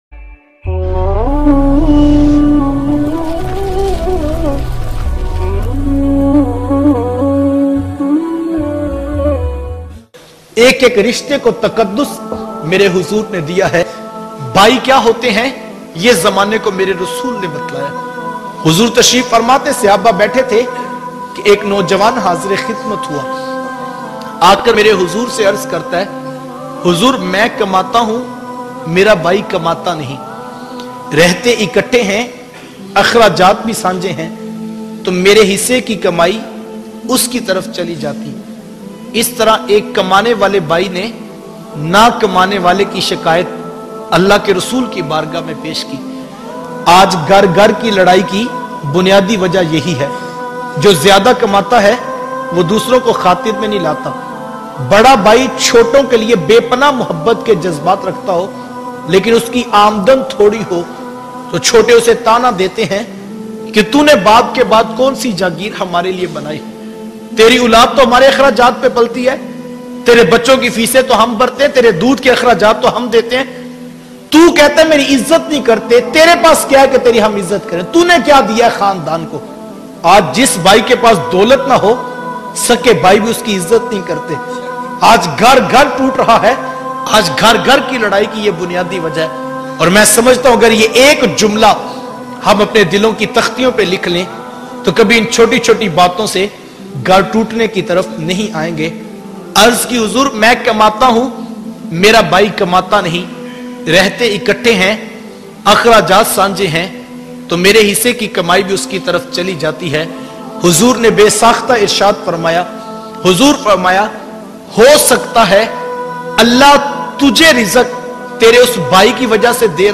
Bhai Ka Rishta - Most Emotional Bayan